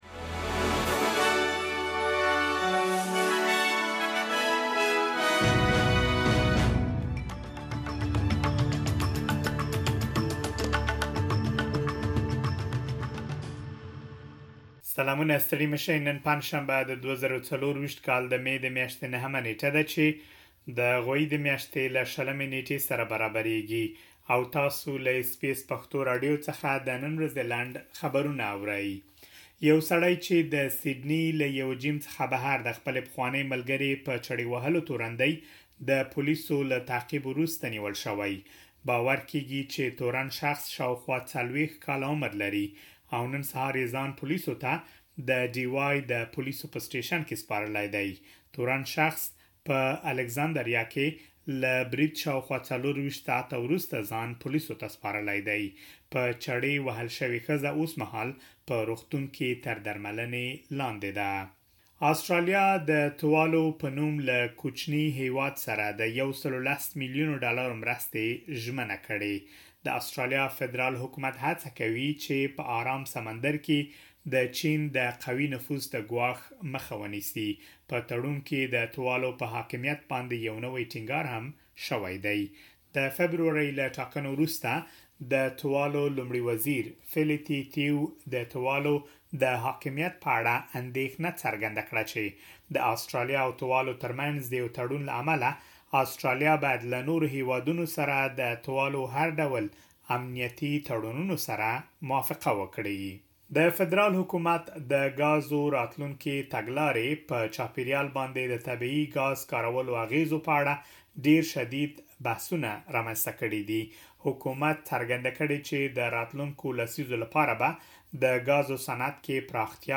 د اس بي اس پښتو راډیو د نن ورځې لنډ خبرونه|۹ مې ۲۰۲۴
د اس بي اس پښتو راډیو د نن ورځې لنډ خبرونه دلته واورئ.